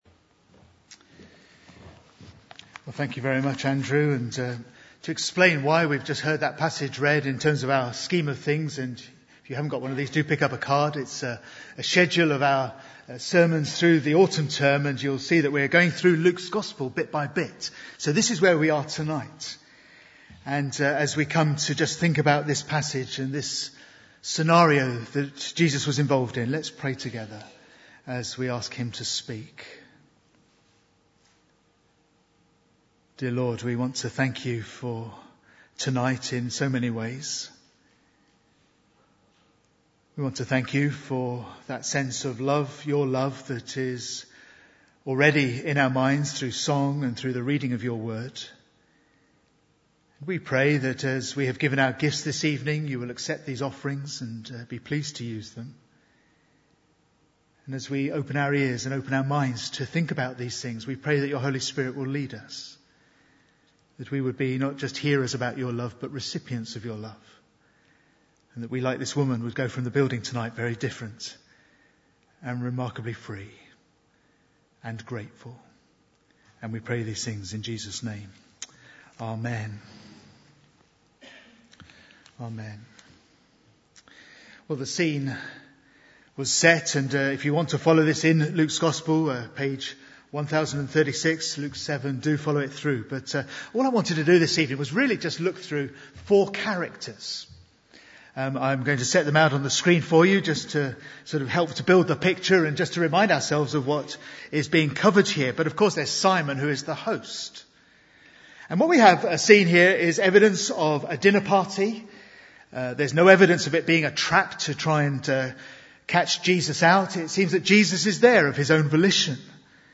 Bible Text: Luke 7:36-50 | Preacher